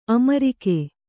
♪ amarike